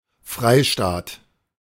In Germany, the term free state (in German, Freistaat pronounced [ˈfʁaɪ̯ˌʃtaːt]
De-Freistaat.ogg.mp3